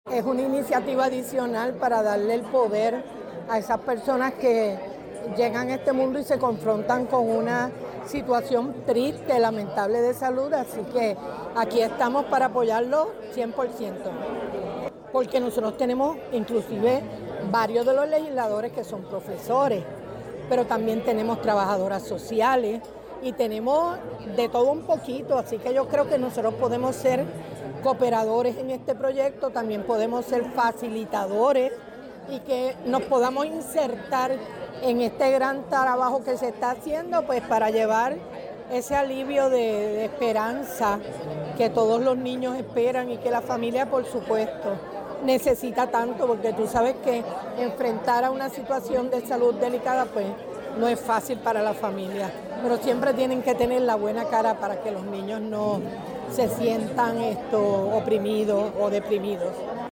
(miércoles 18 de marzo de 2026)-La representante Lourdes Ramos durante la apertura de la exhibición “Siente el Ritmo” dijo que es una iniciativa adicional para darle el poder a esas personas que llegan a este mundo y se confrontan con una situación triste, lamentable de salud.